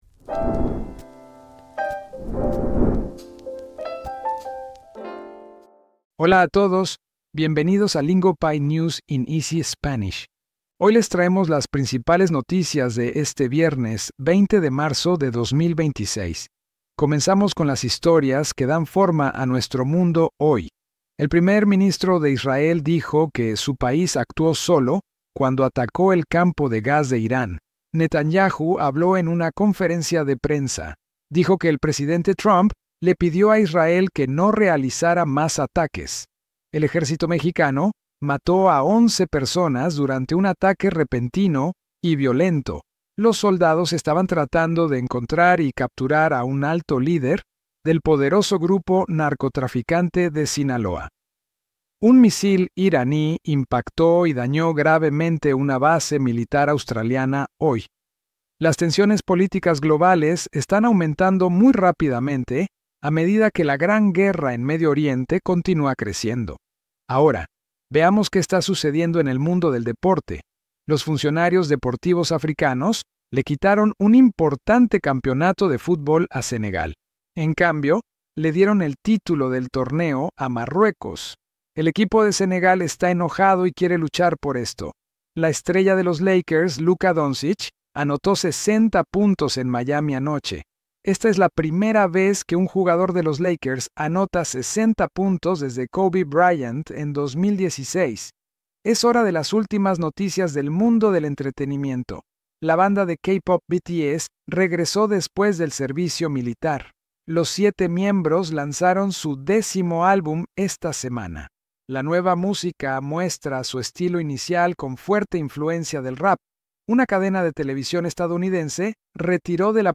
Lingopie News in Easy Spanish slows the biggest stories down into clear, beginner-friendly Spanish, so your listening practice feels like progress instead of punishment.